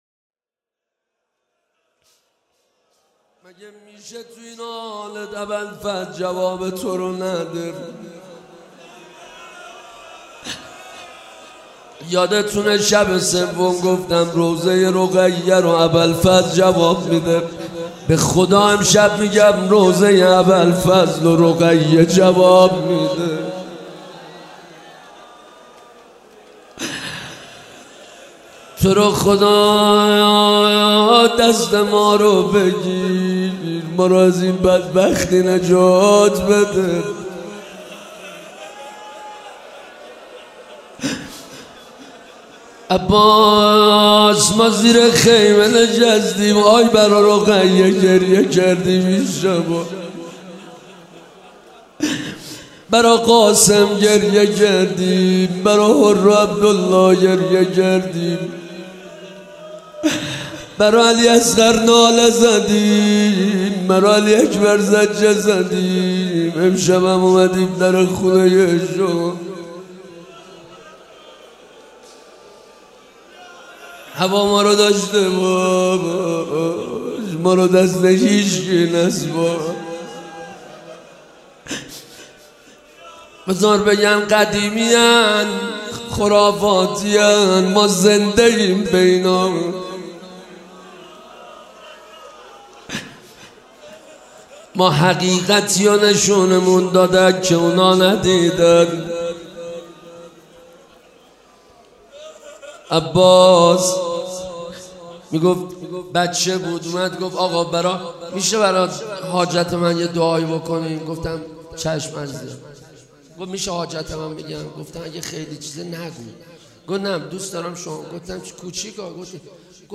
روضه حضرت عباس